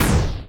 poly_explosion_fireball1.wav